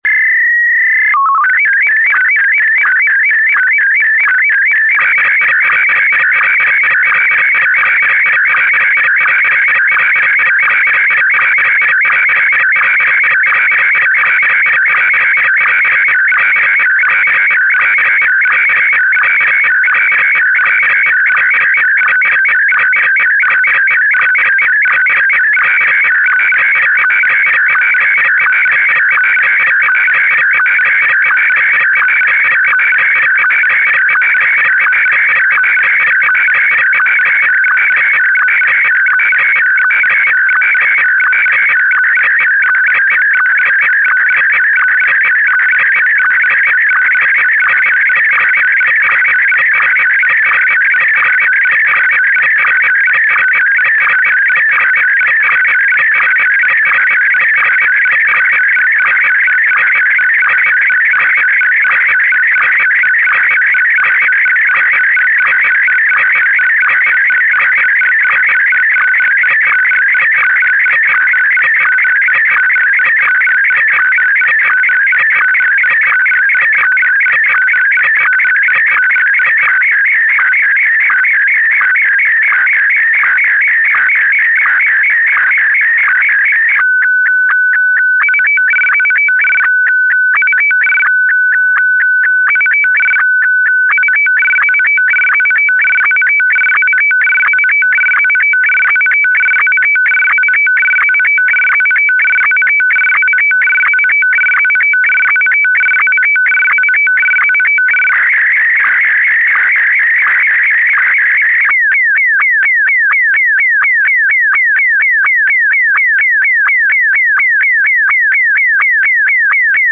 Globo 4: Modos en SSTV - Imagen de prueba
Wraase 180s
wraase_180.wav